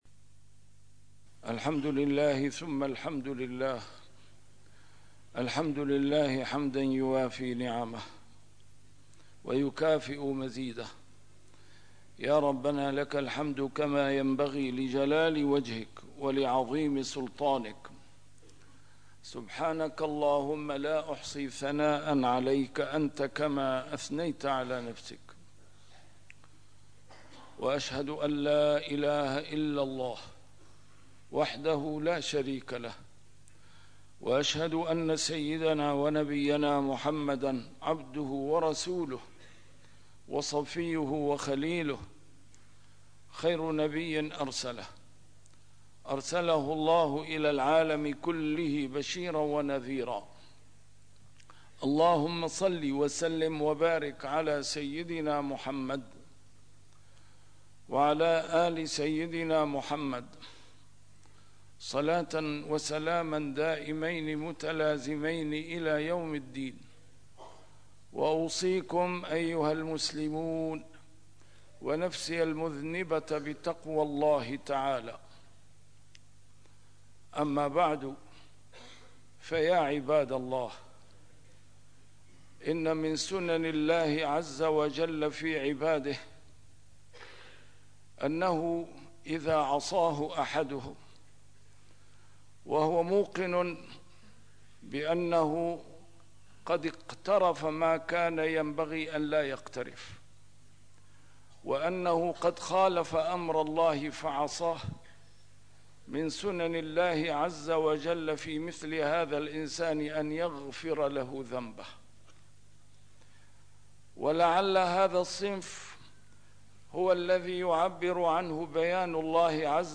A MARTYR SCHOLAR: IMAM MUHAMMAD SAEED RAMADAN AL-BOUTI - الخطب - ترى هل يشهد العالم اليوم موت الأزهر